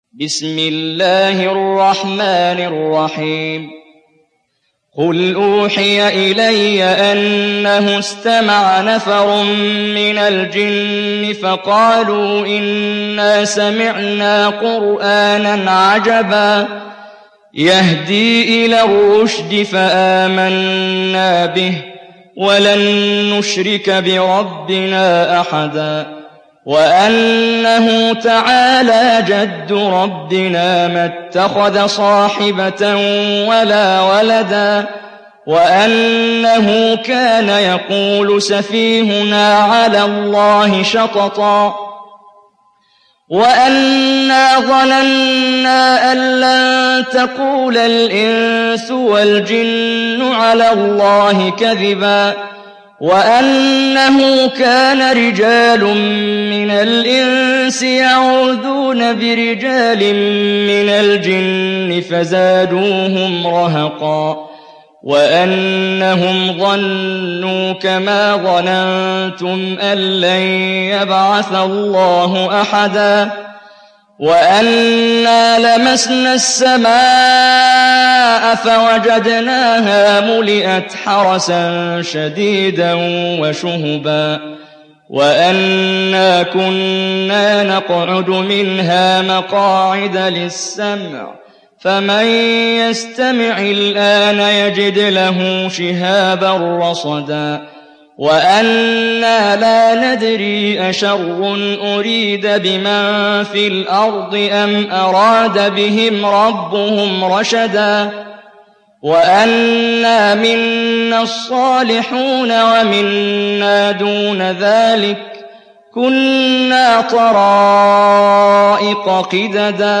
Cin Suresi İndir mp3 Muhammad Jibreel Riwayat Hafs an Asim, Kurani indirin ve mp3 tam doğrudan bağlantılar dinle